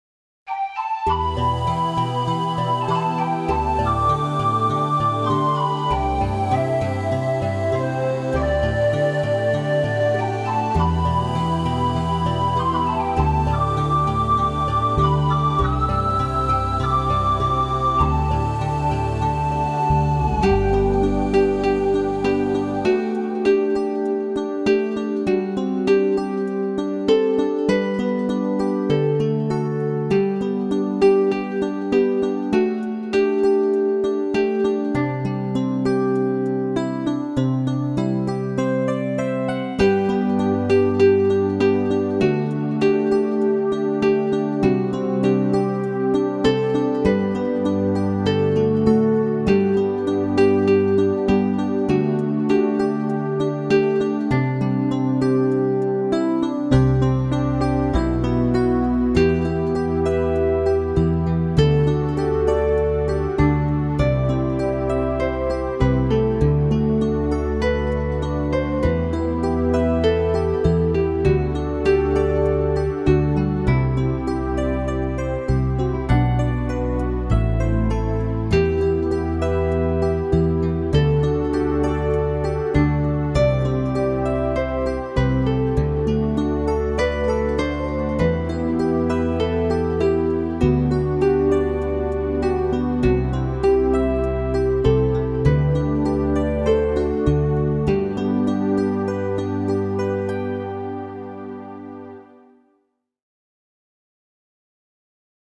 soundmixbegeleiding